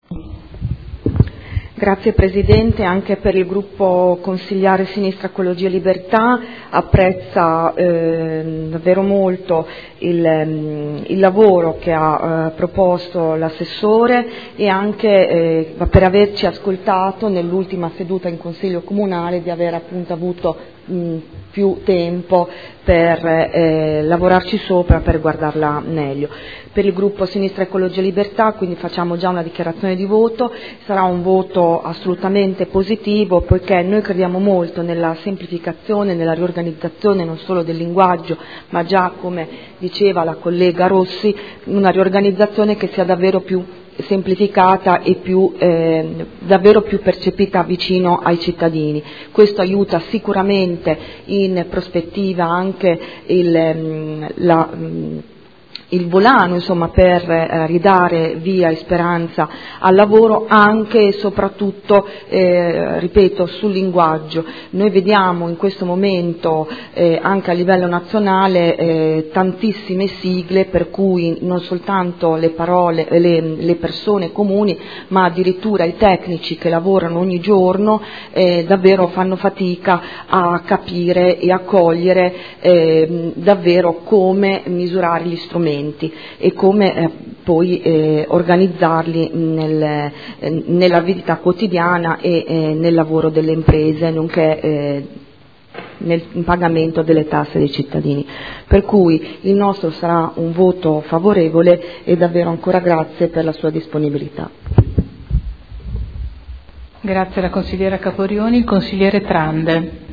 Ingrid Caporioni — Sito Audio Consiglio Comunale
Proposta di deliberazione: Adeguamento alla L.R. 15/2013 – Variante al Regolamento Urbanistico Edilizio (RUE) – Adozione. Dibattito e dichiarazione di voto